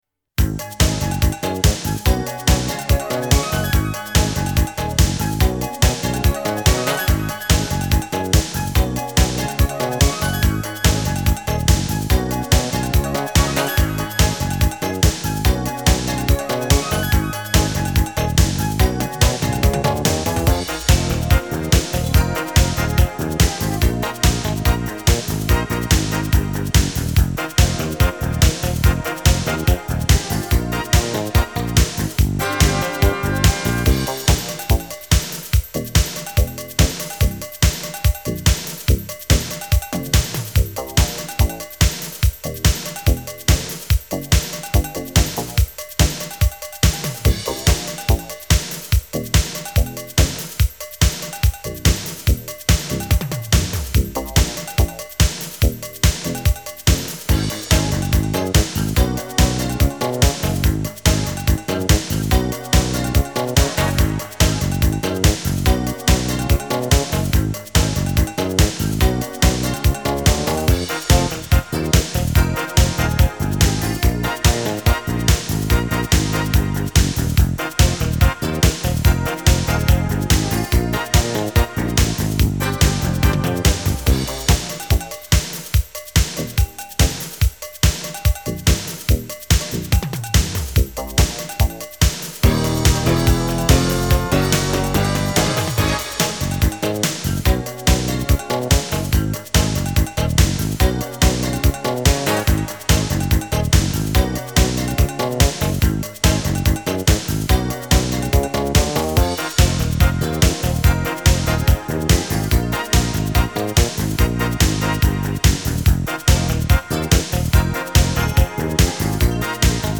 Тип:Score